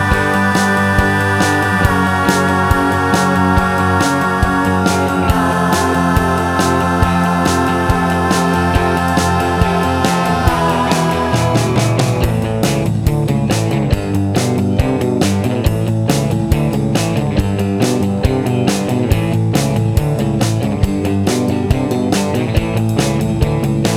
No Harmony Pop (1950s) 2:15 Buy £1.50